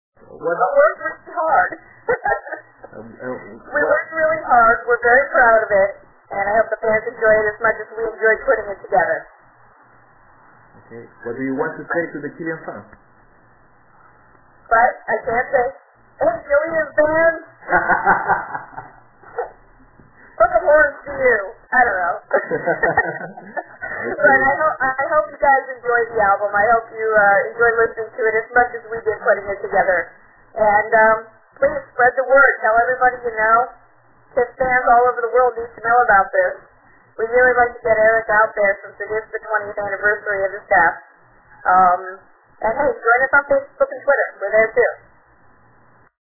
Entrevista: